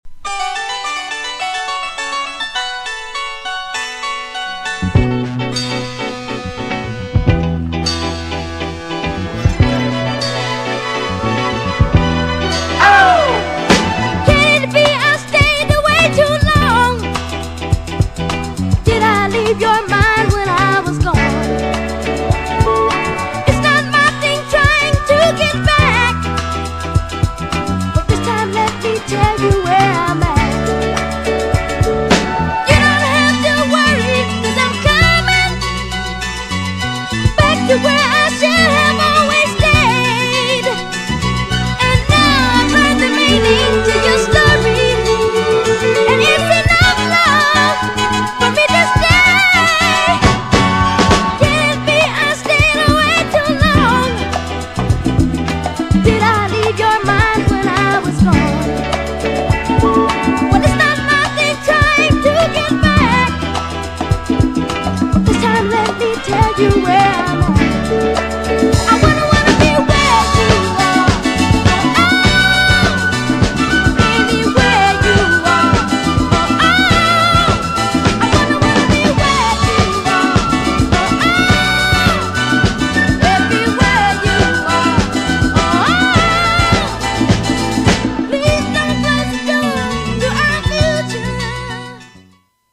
GENRE Dance Classic
BPM 86〜90BPM